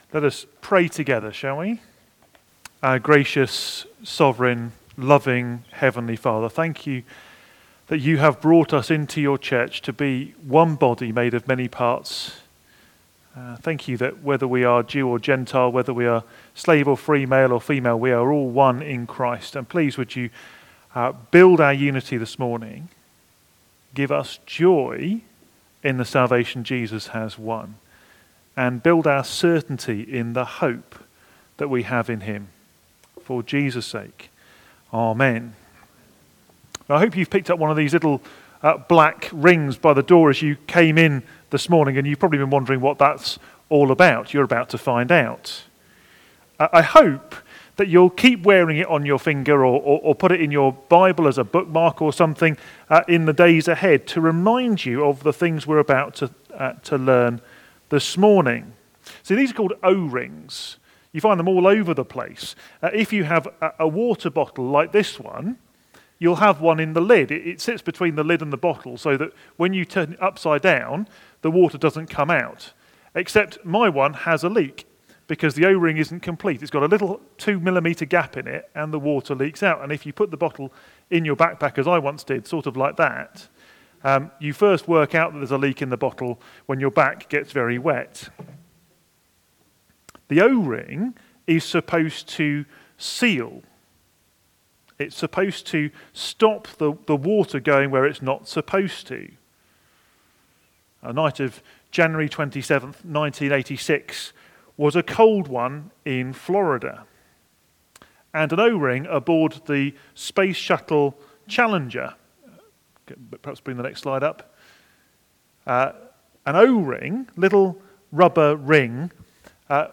Media Library We record sermons from our Morning Prayer, Holy Communion and Evening services, which are available to stream or download below.
Passage: Galatians 2:11-21 Series: In Christ Alone Theme: Sermon Search